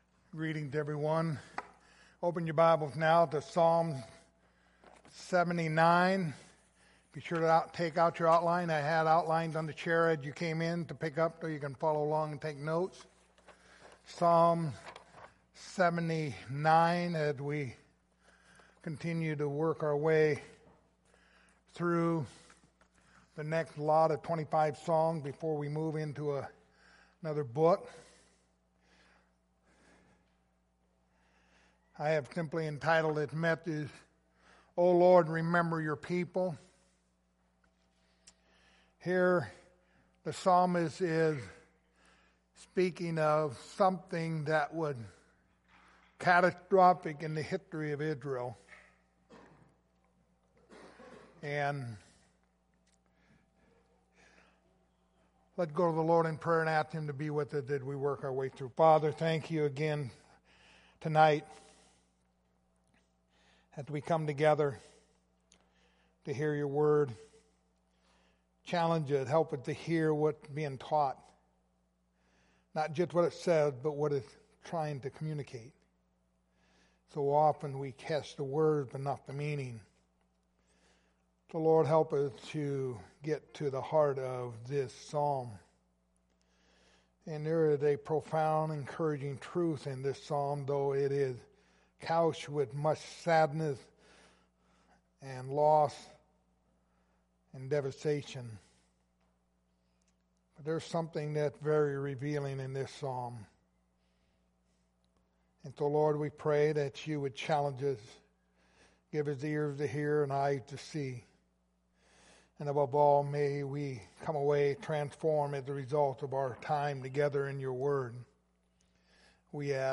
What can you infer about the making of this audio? Passage: psalms 79:1-13 Service Type: Sunday Evening